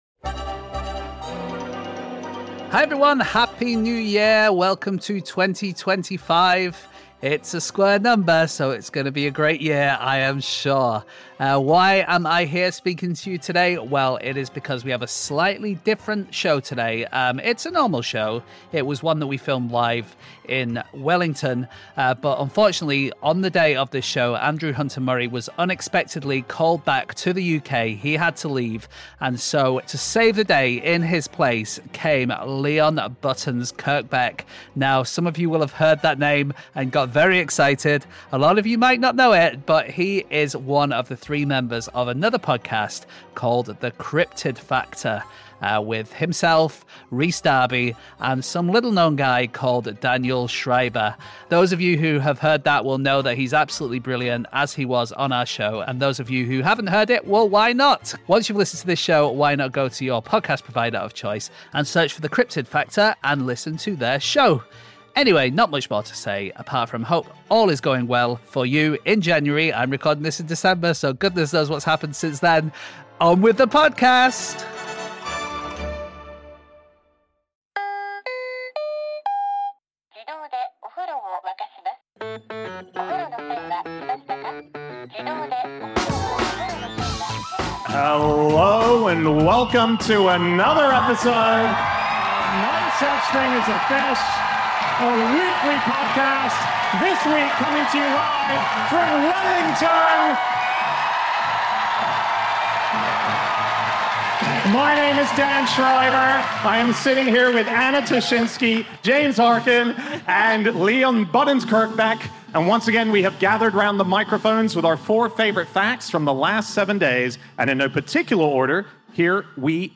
Live from Wellington